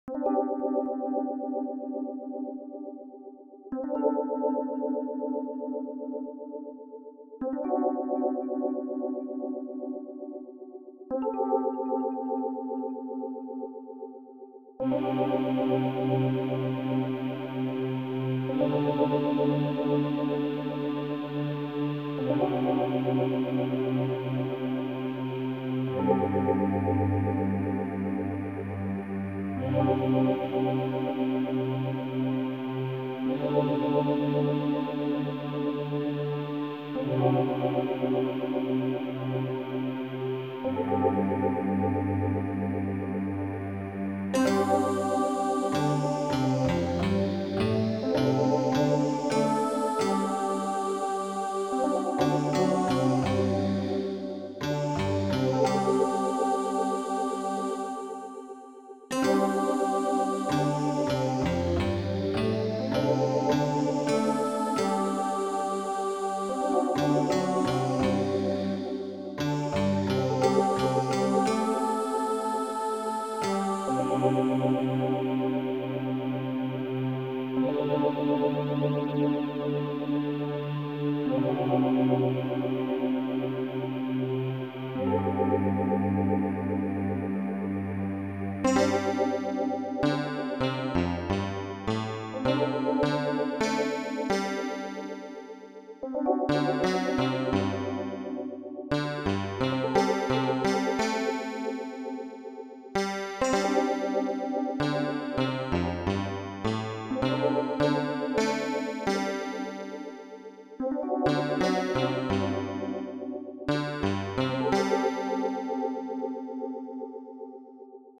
They’re all simple, and quite repetitive.
For these especially, I tried to make the rhythms and notes sound particularly, for lack of a better word, “odd”, to fit the sort of surreal feel I want the game to have.